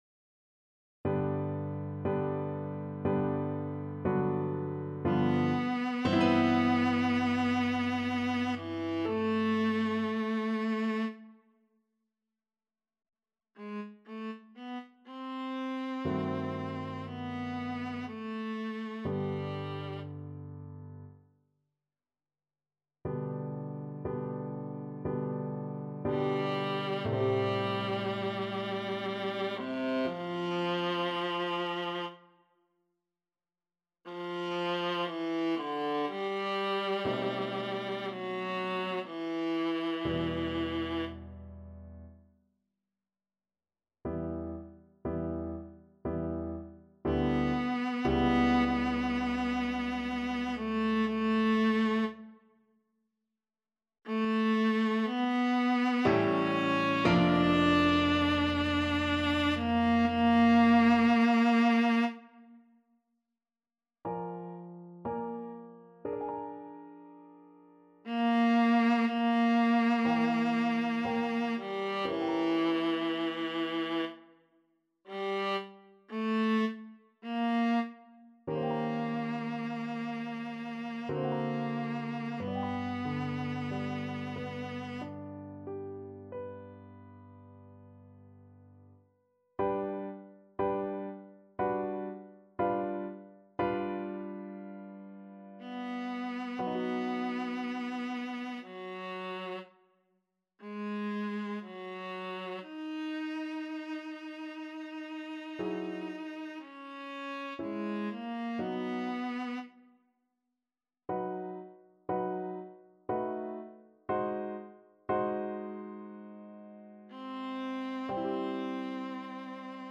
Viola
3/4 (View more 3/4 Music)
~ = 60 Langsam, leidenschaftlich
G major (Sounding Pitch) (View more G major Music for Viola )
Classical (View more Classical Viola Music)
ich-liebe-dich-s-315_VLA.mp3